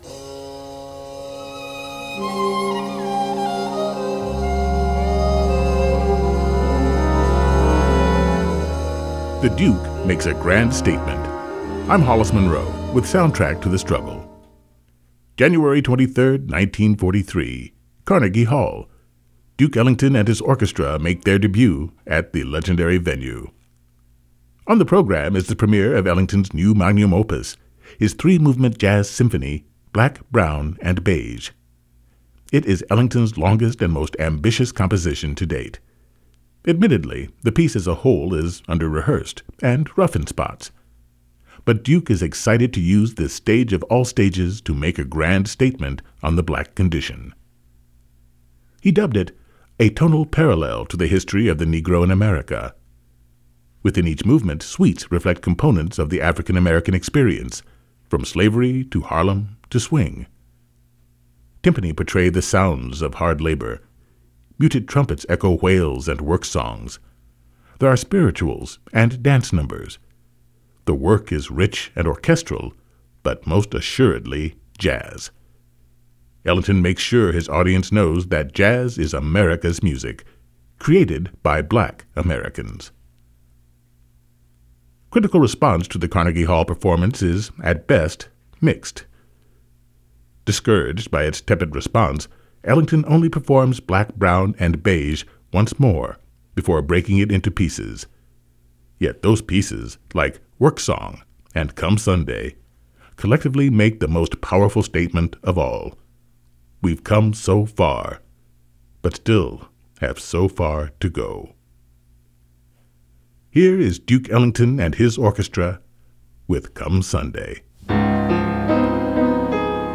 Tympani portray the sounds of hard labor. Muted trumpets echo wails and work songs.
The work is rich and orchestral, but most assuredly jazz.